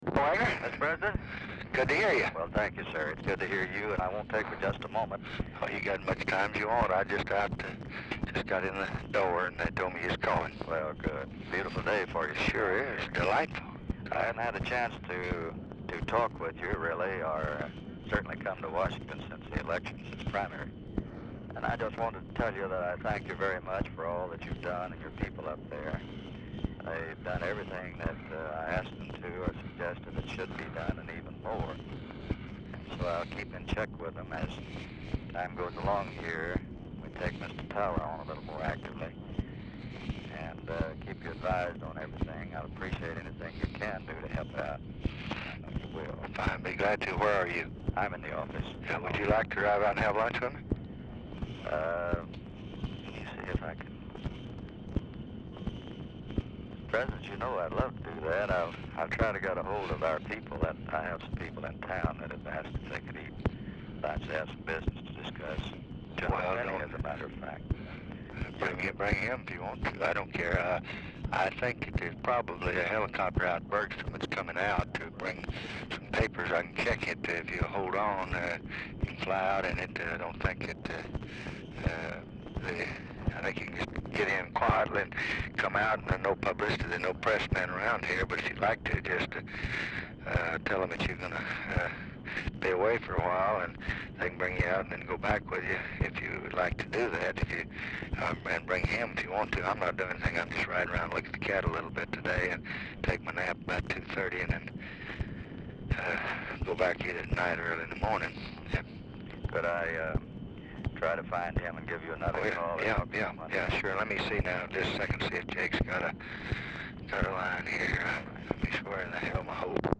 Telephone conversation # 10214, sound recording, LBJ and WAGGONER CARR, 6/6/1966, 10:25AM
RECORDING ENDS BEFORE CONVERSATION IS OVER
Format Dictation belt
Location Of Speaker 1 LBJ Ranch, near Stonewall, Texas